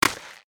sound effects
MG_sfx_vine_game_catch.ogg